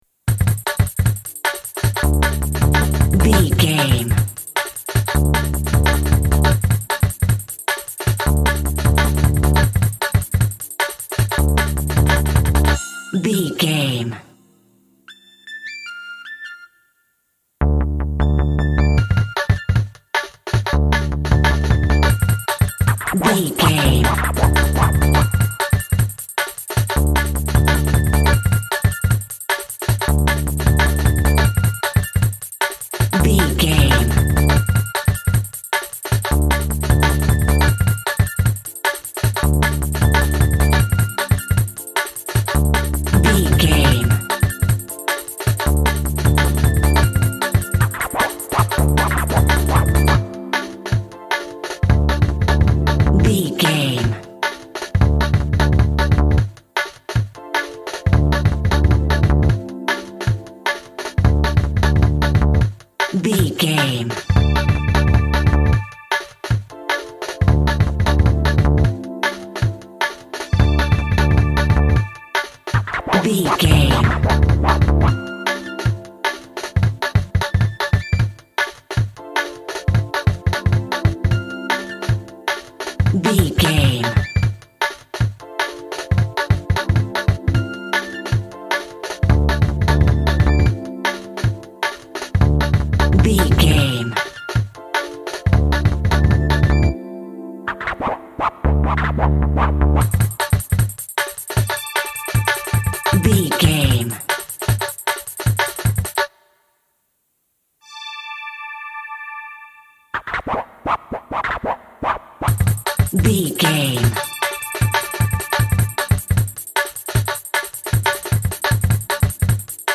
Aeolian/Minor
Fast
futuristic
hypnotic
industrial
mechanical
driving
energetic
frantic
drum machine
synth lead
synth bass